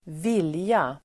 Uttal: [²v'il:ja]